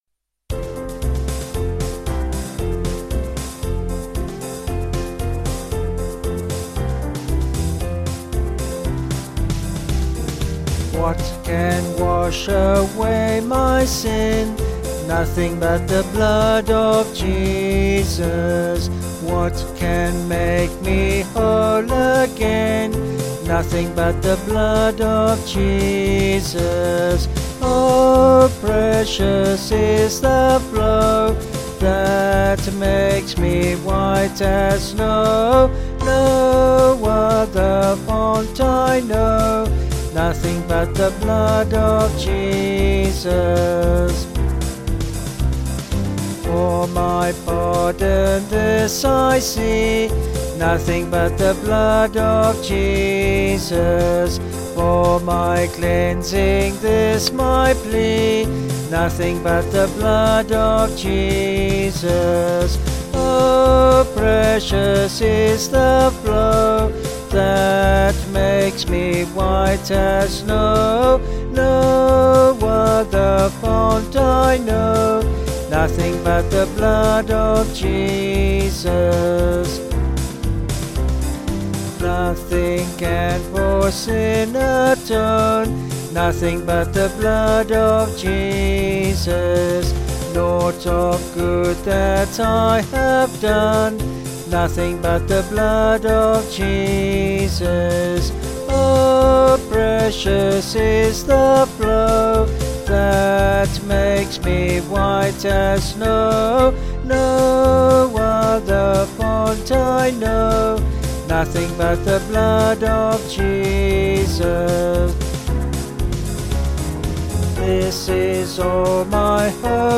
Vocals and Band 263.6kb Sung Lyrics